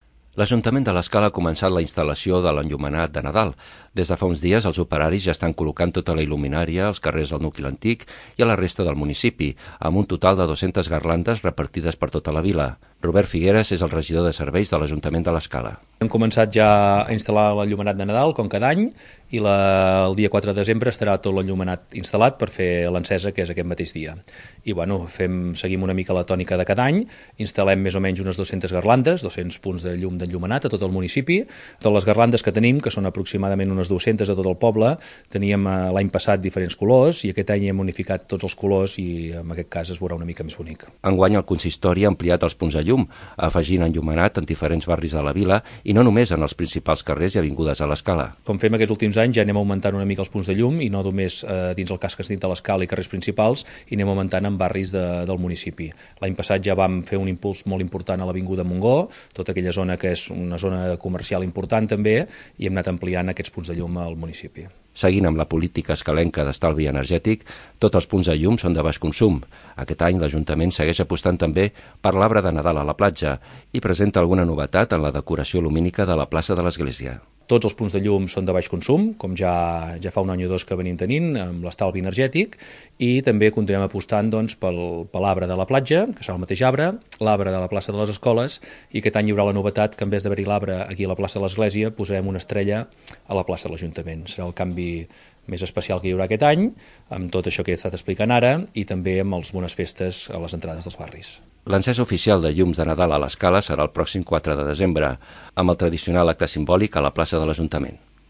Robert Figueras és el regidor de seguretat i serveis urbans.